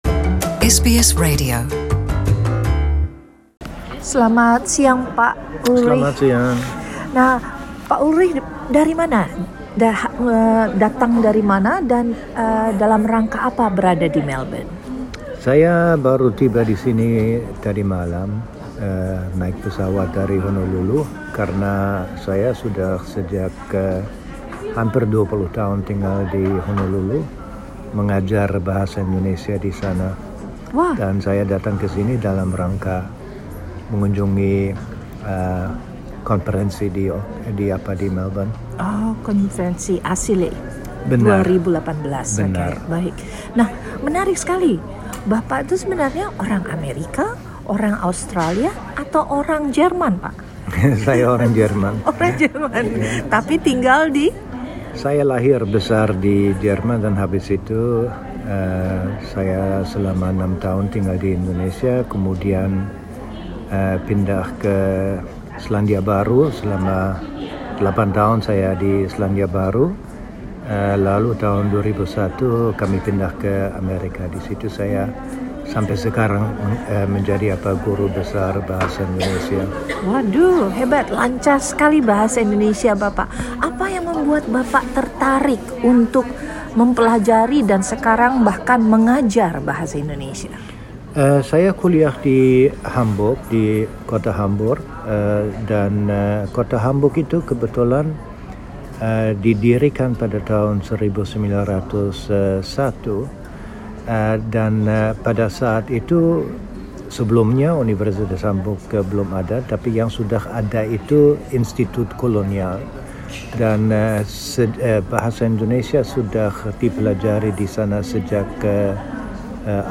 berbincang dengan SBS Indonesian pada konperensi ASILE 2018 di Melbourne.